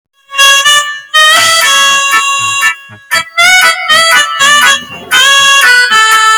Download Mexican sound effect for free.